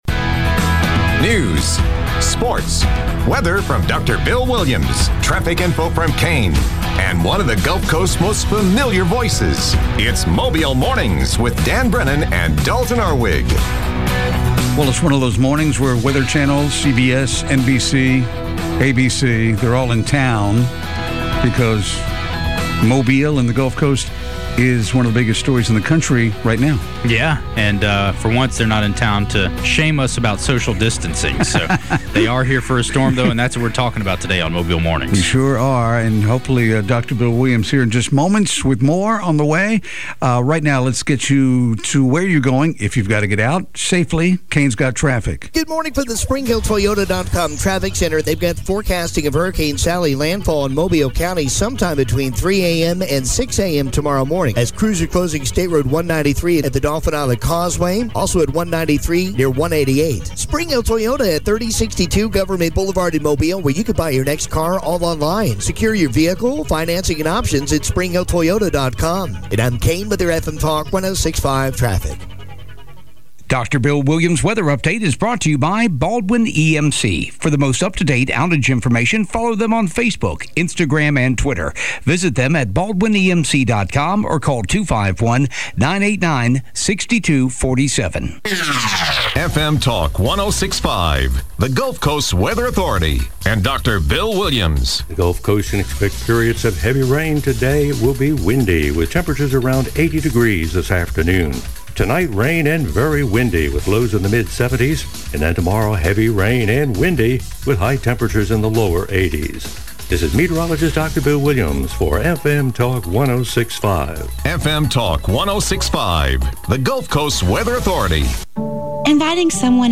report on local news and sports.
Hurricane Sally coverage continues... Mayor Jeff Collier updating conditions on Dauphin Island